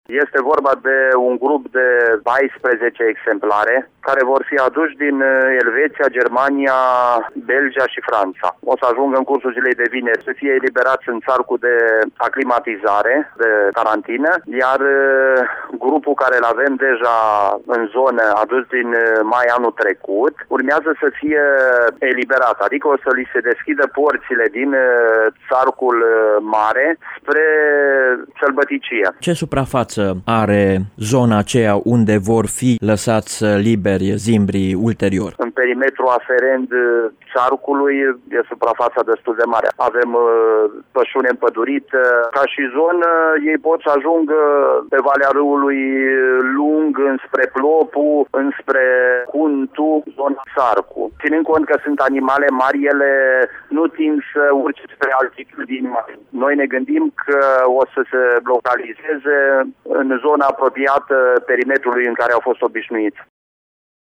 Cu amănunte, Petru Vela – primarul comunei Armeniş: